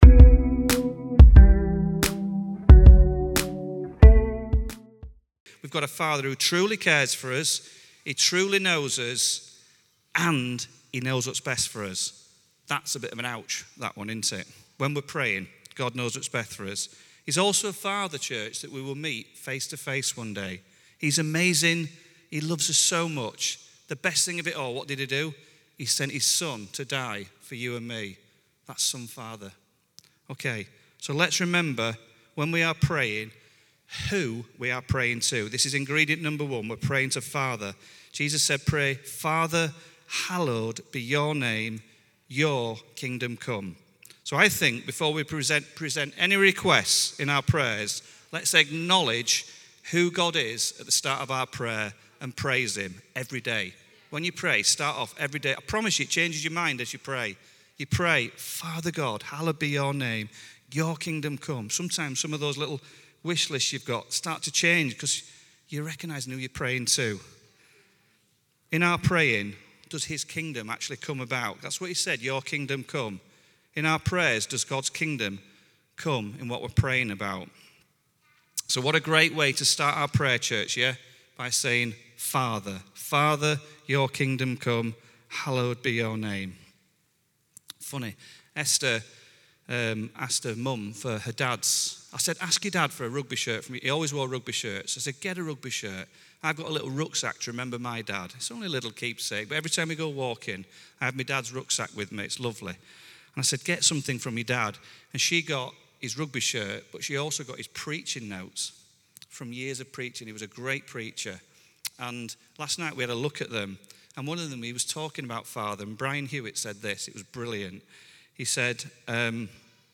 Rediscover Church Newton Abbot | Sunday Messages